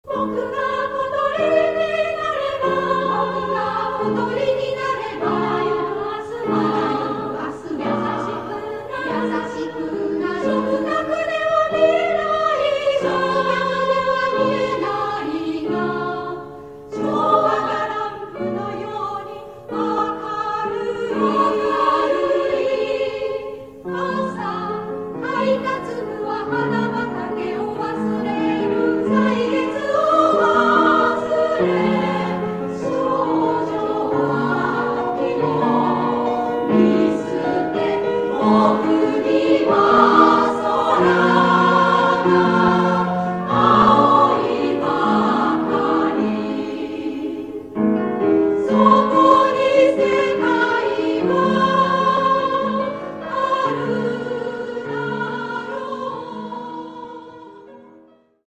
女声合唱団「道」 はじめてのコンサート
女声合唱団「道」のはじめてのコンサートを平成６年４月２４日（日）に開きました。